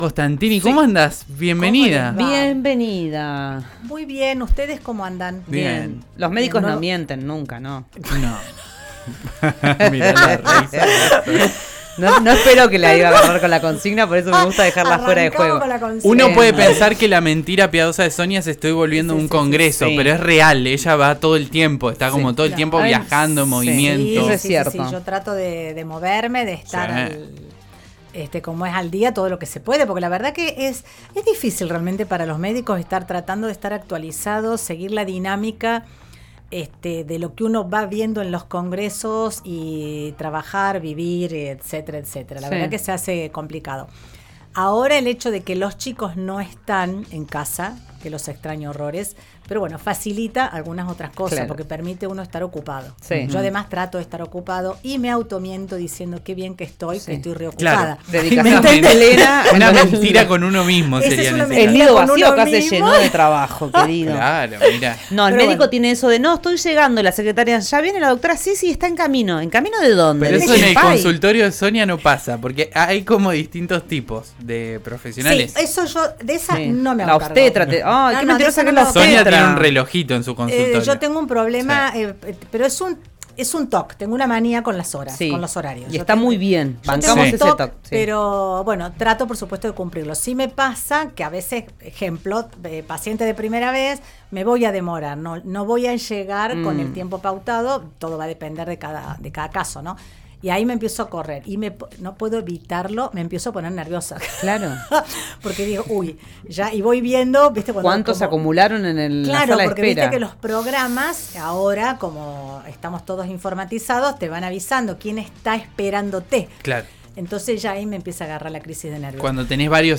Escuchá la columna de salud de El Diario del mediodía, por RÍO NEGRO RADIO